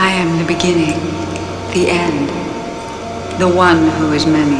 在這裡有一些影集與電影裡，精彩對白的語音連結
博格女王的語音一 (Wav, 102KB)